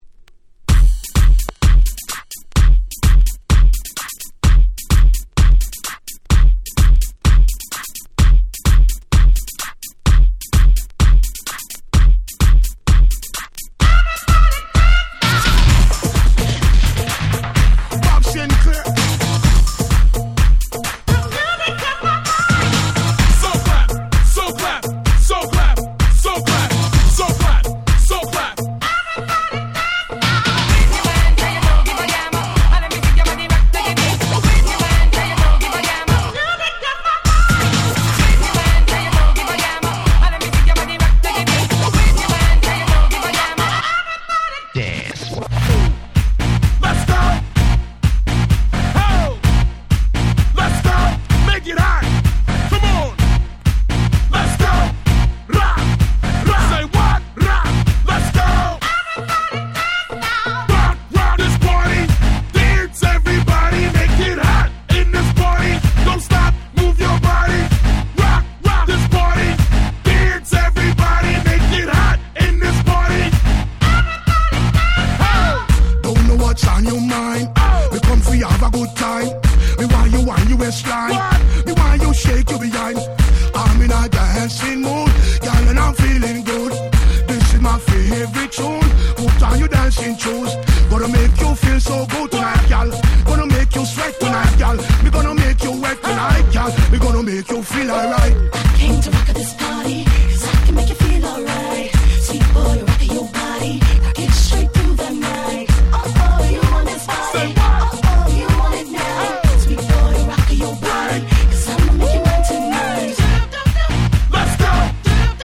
夏ソング アゲアゲ EDM レゲエ Reggae 00's R&B